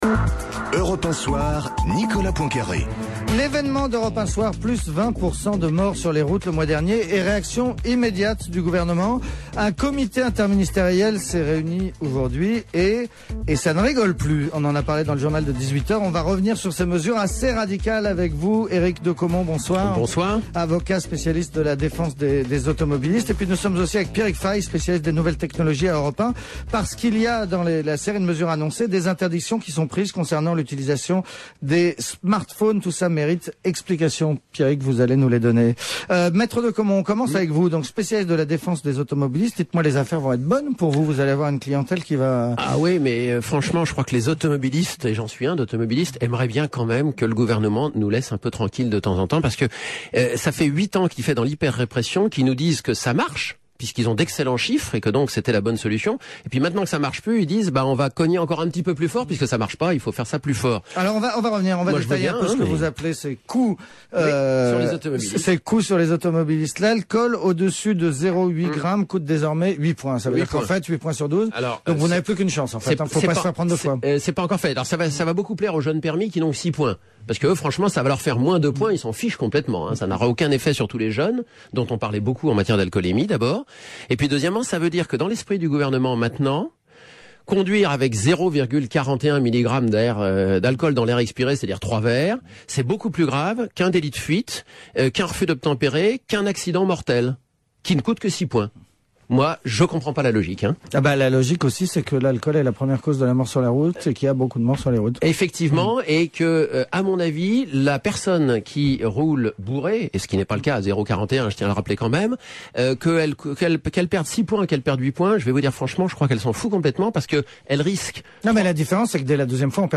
interviewé par Nicolas Poincaré sur l’antenne d’Europe 1.